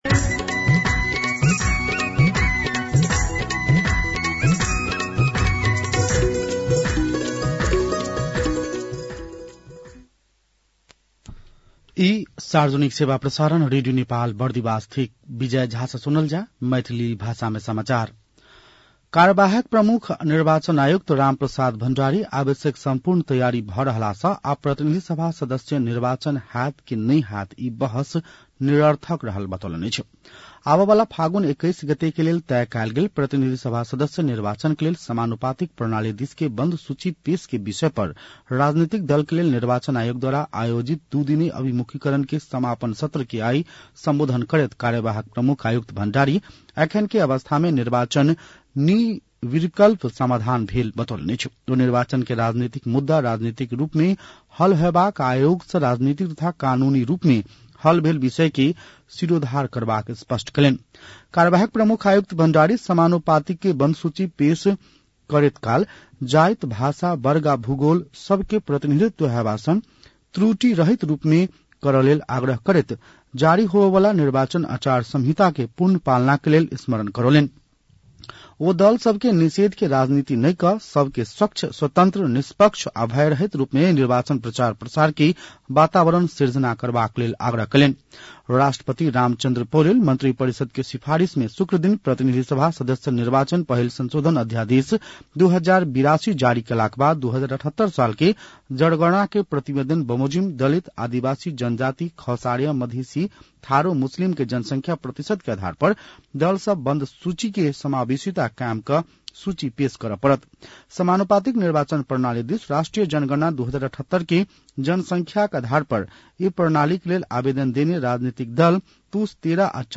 मैथिली भाषामा समाचार : ९ पुष , २०८२